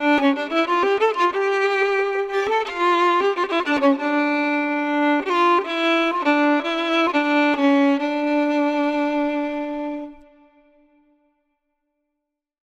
لوپ ویولن لایو Azarbaijani Violin | هنر صدا
demo-Azarbaijani Violin.mp3